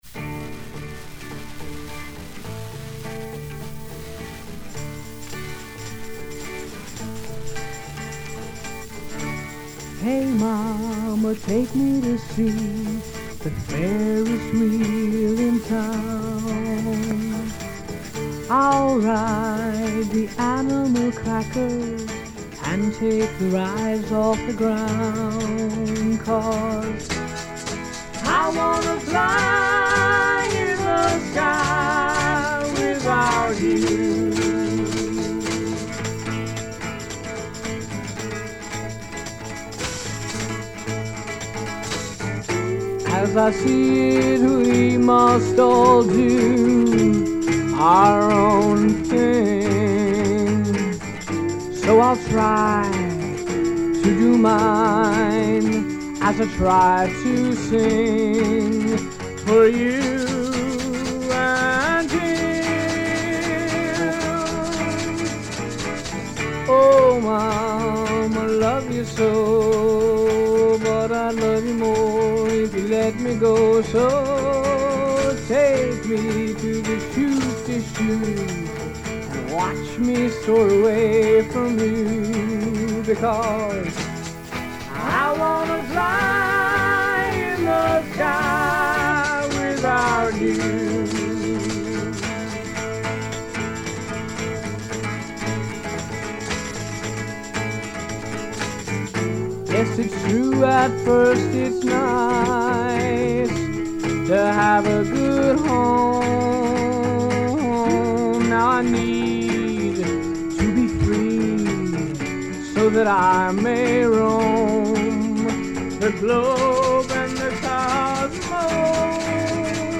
singing back up harmonies.